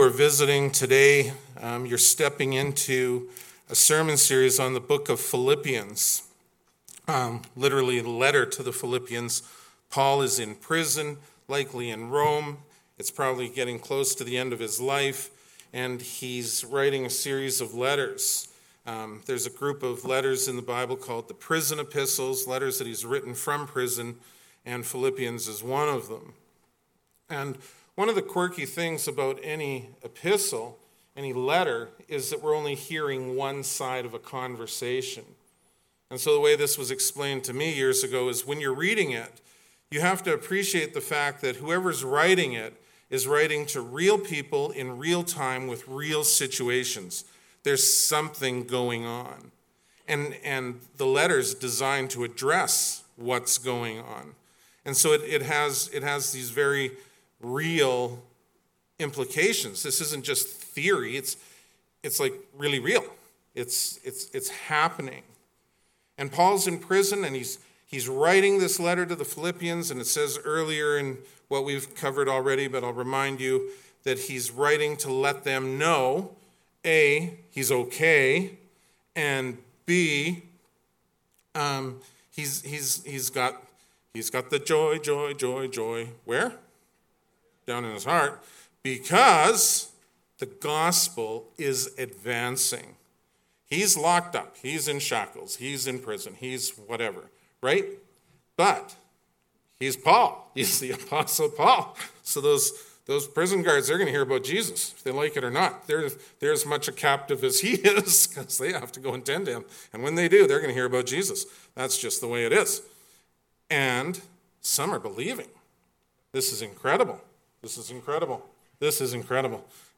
2024 Making Our Joy Complete Preacher